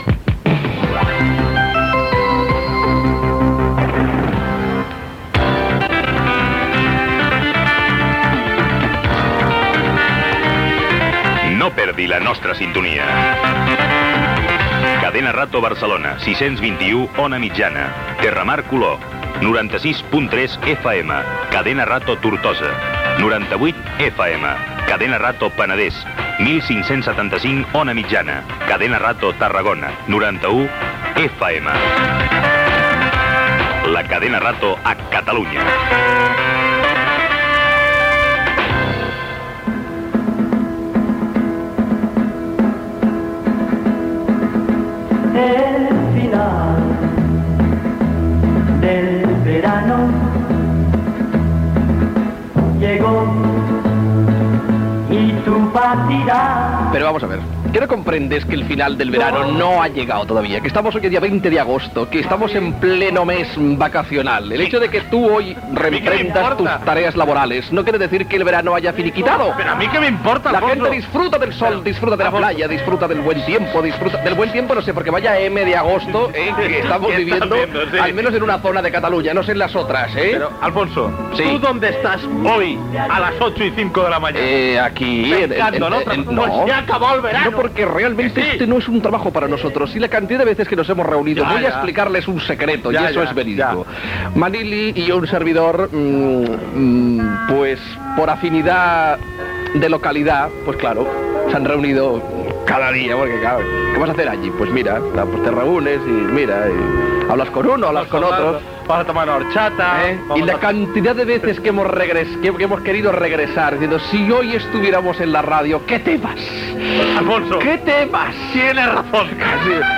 Indicatiu de la Cadena Rato a Catalunya, cançó, diàleg sobre la fi de l'estiu, el paper pintat de l'estudi, equip del programa, repàs humorístic a l'actualitat amb una imitació del president de la Genealitat Jordi Pujol
Entreteniment